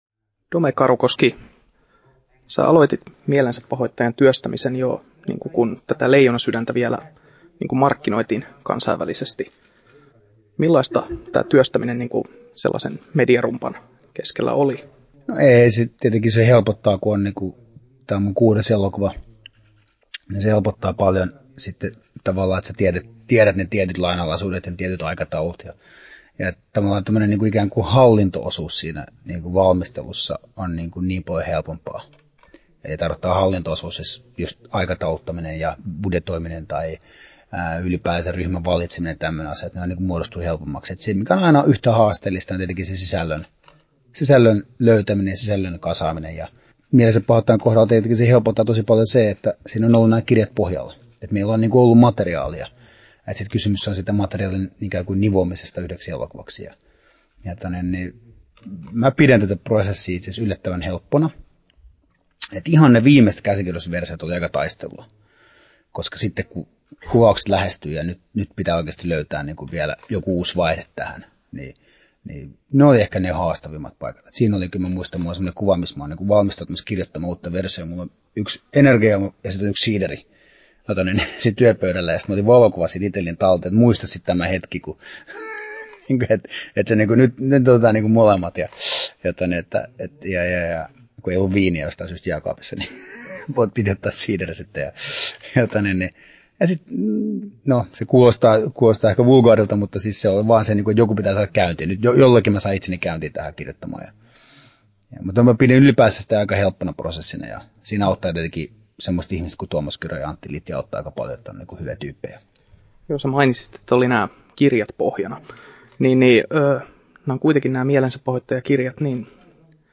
Haastattelussa Dome Karukoski Kesto: 12'28" Tallennettu: 20.8.2014, Turku Toimittaja